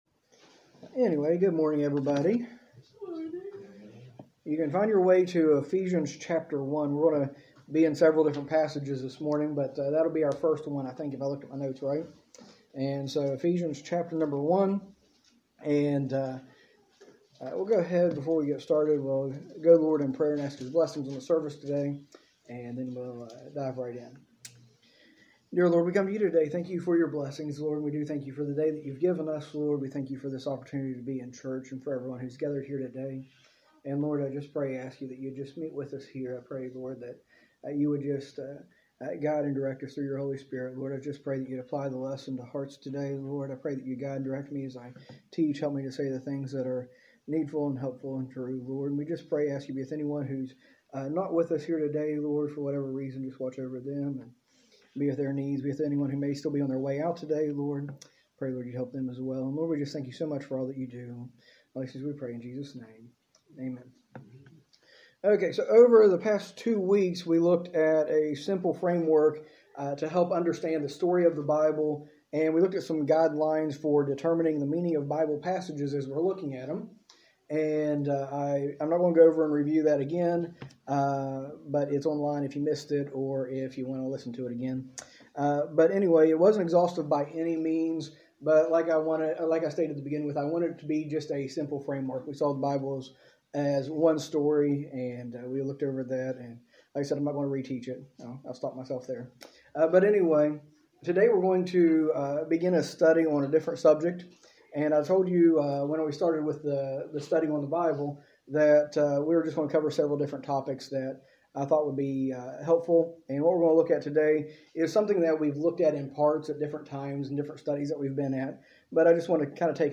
In this series, taught during our adult Sunday School, we explore basic principles and teachings of Christianity that will help us live our faith in everyday life.